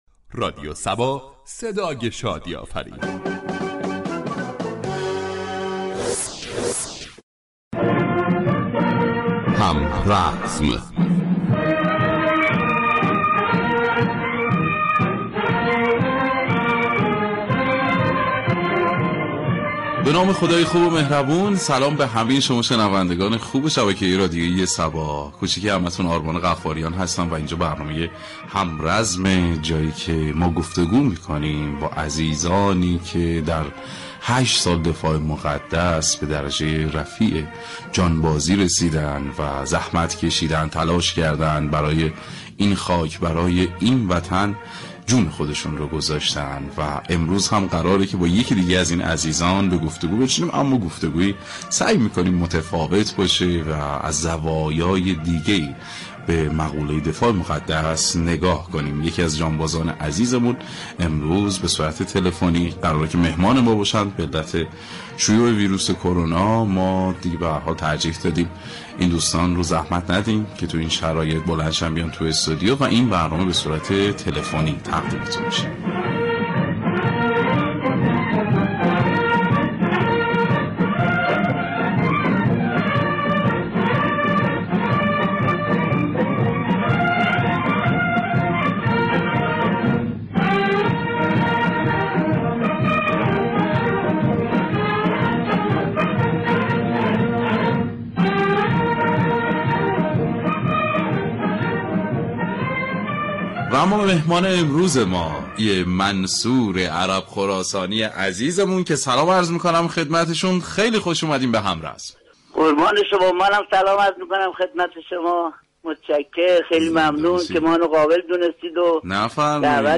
"همرزم" ویژه برنامه ای است ،با محوریت گفتگو با یكی از جانبازان یا ایثارگرانی كه فرصت حضور در جبهه‌های جنگ را داشته اند.